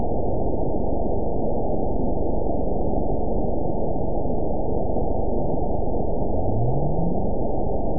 event 911478 date 03/01/22 time 14:32:49 GMT (3 years, 2 months ago) score 9.52 location TSS-AB05 detected by nrw target species NRW annotations +NRW Spectrogram: Frequency (kHz) vs. Time (s) audio not available .wav